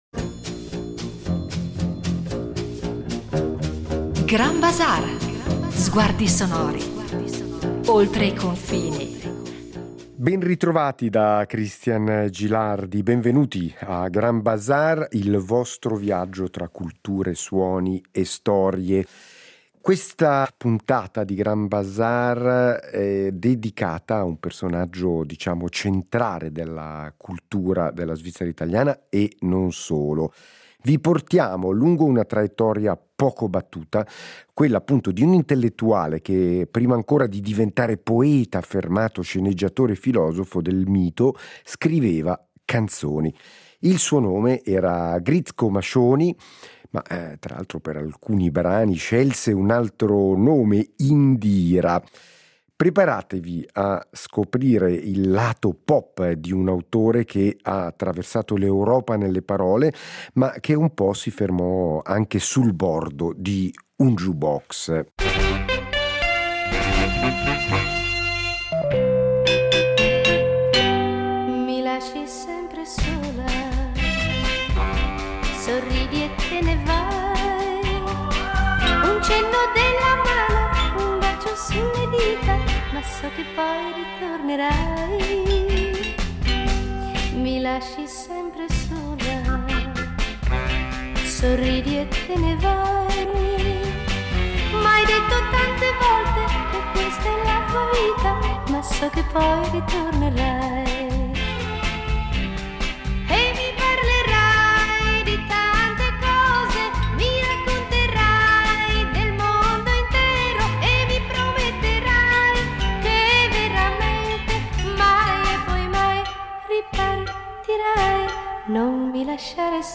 La puntata includerà anche ascolti esclusivi tratti dagli archivi RSI, restituendo il ritratto di un artista poliedrico, capace di dialogare con la canzone popolare senza rinunciare alla sua eleganza poetica.